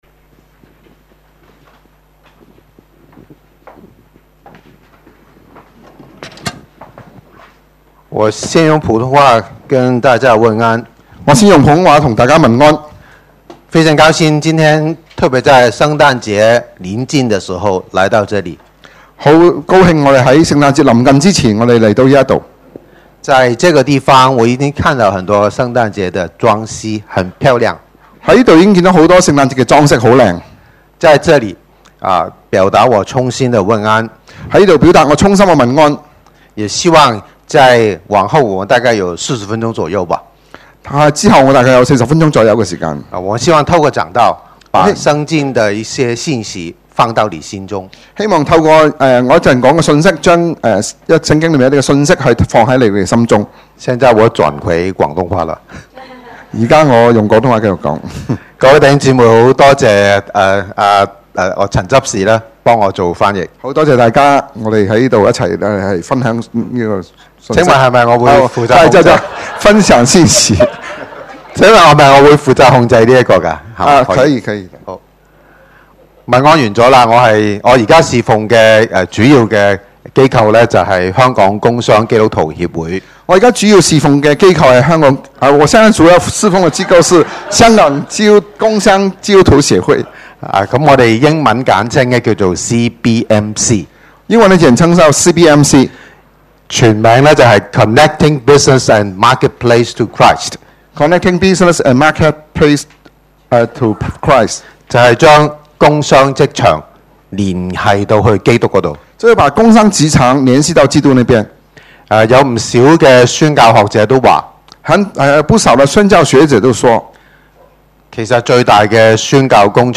Posted in 主日崇拜 ← Newer 講道 Older 講道 →